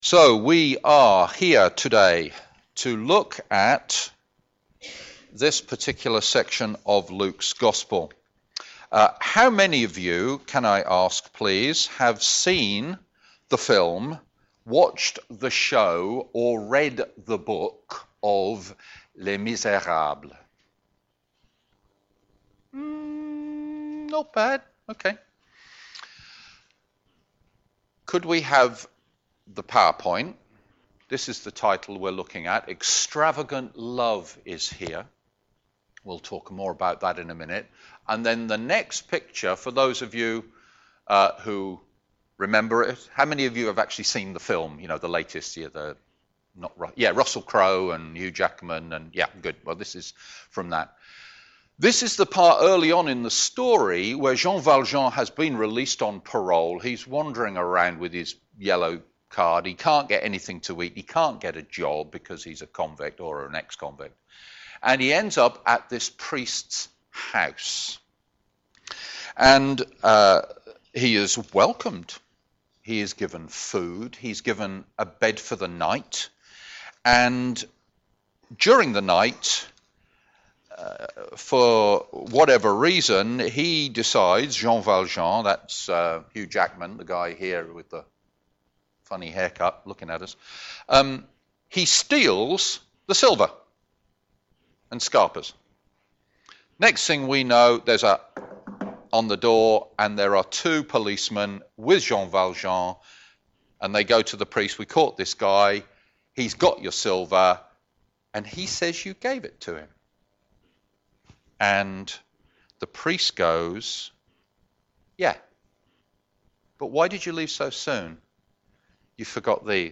Media for a.m. Service on Sun 16th Oct 2016 10:30
Theme: Thankfulness Sermon